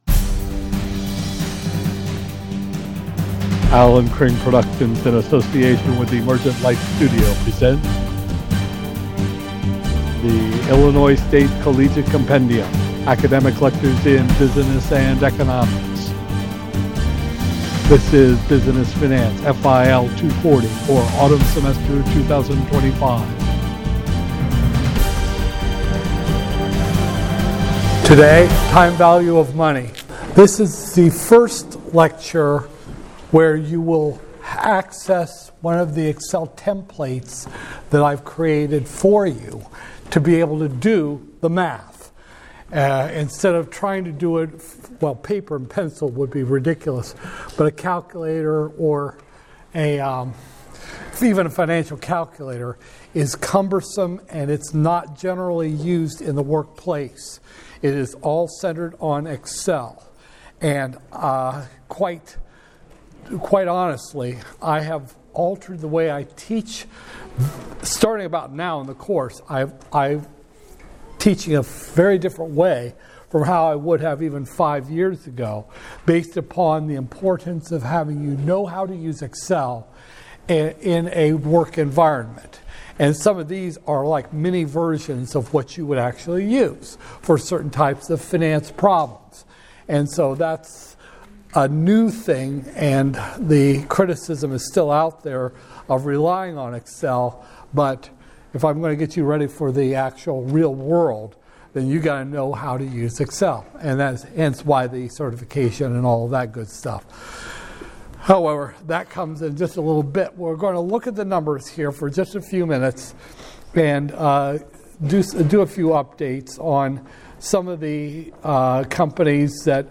Business Finance, FIL 240-002, Spring 2025, Lecture 8